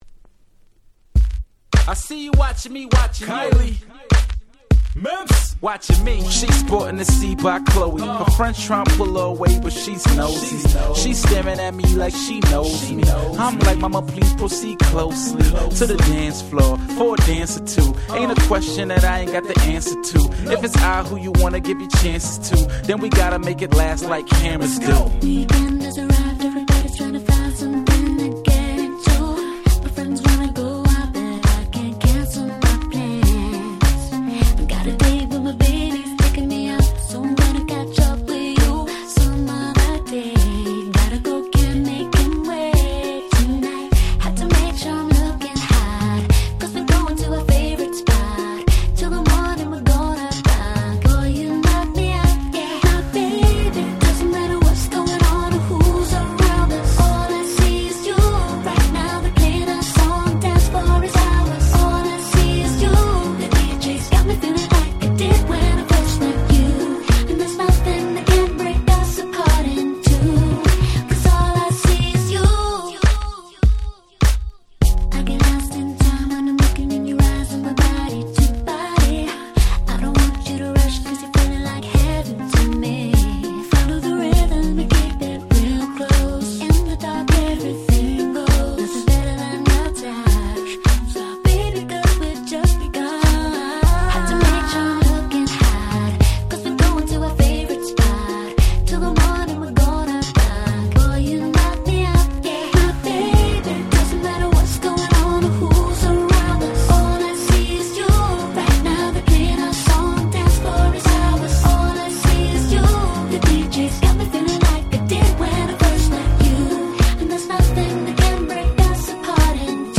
07' Super Nice R&B !!
キラキラでめちゃ良い曲！！